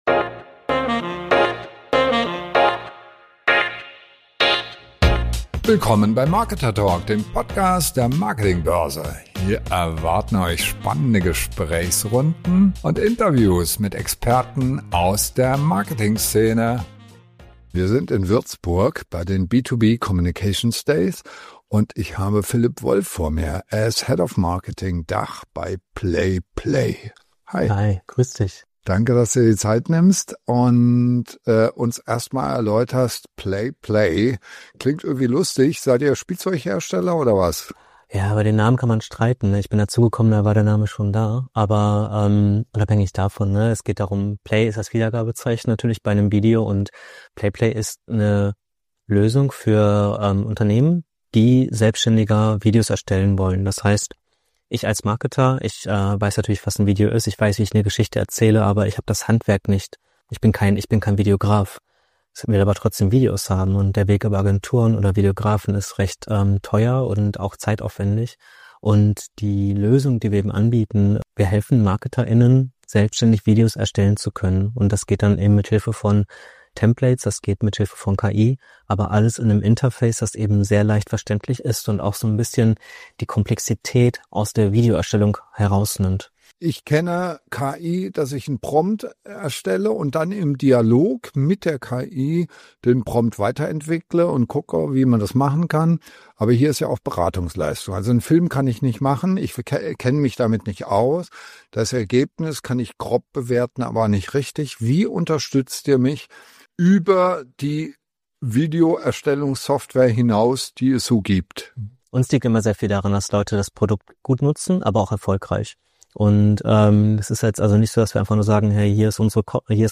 Video Content braucht nicht kompliziert zu sein. Wie Marketing-Teams mit den richtigen Tools und Templates eigenständig professionelle Videos produzieren – ohne Agentur, ohne großes Budget. Ein Gespräch über Realität und Potential von KI-gestützter Videoerstellung.